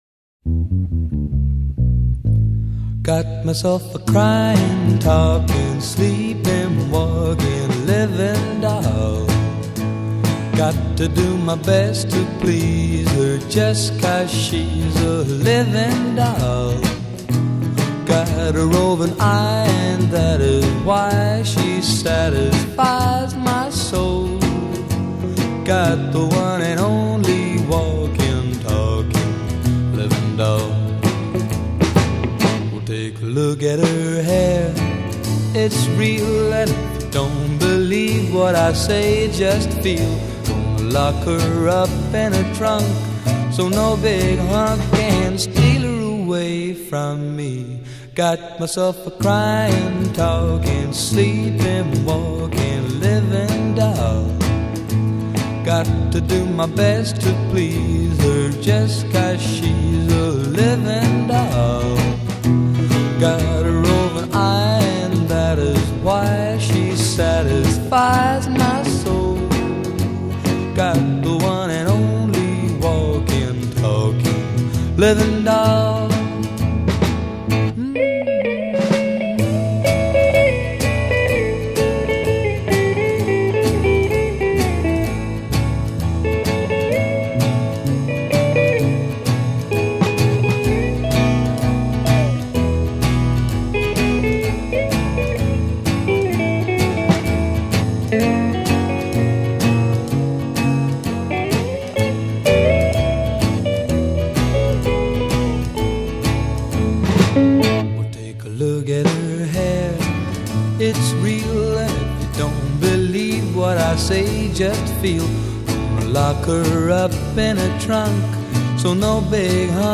Intro 0:00 2 bass solo
A verse 0: 16 vocal solo with ensemble a
A' break : 16 guitar solo on verse material
B chorus : 8 vocal solo with ensemble b